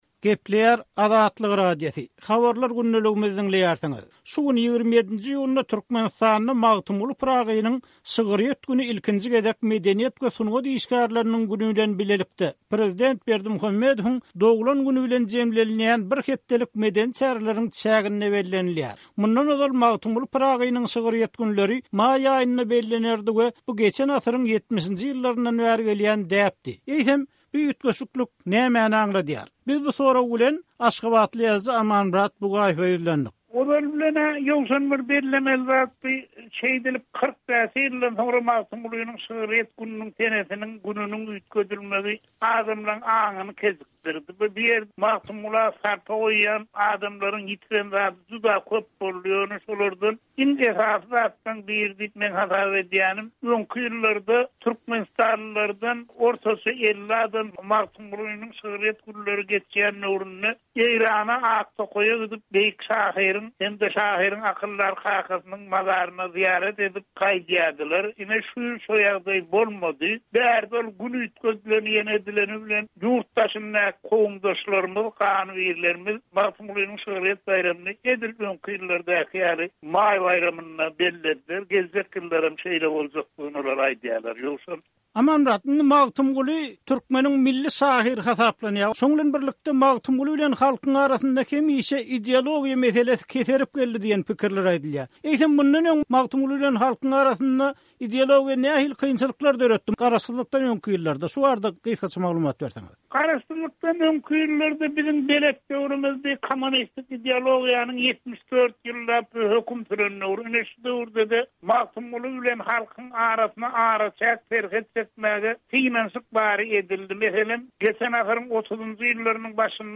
Magtymguly günleri baradaky söhbetdeşligimizi